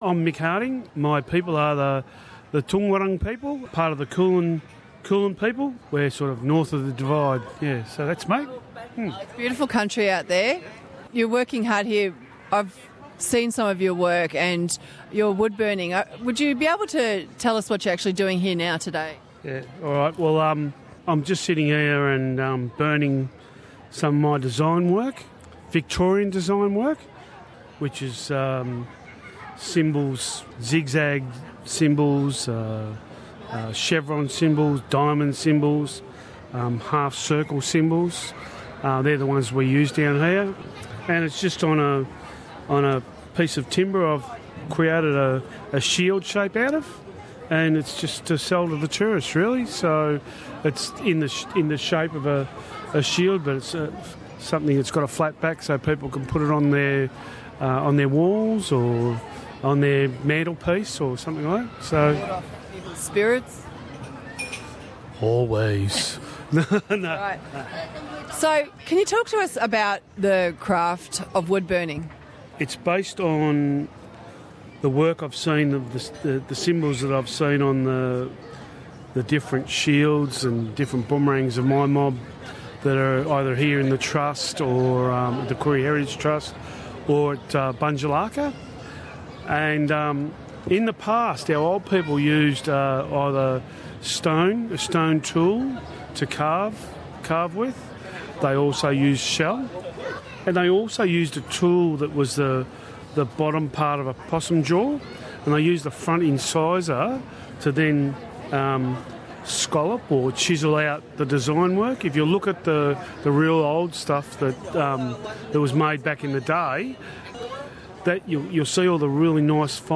Warning to Aboriginal and Torres Strait Islanders listeners that a deceased family member is mentioned in this interview.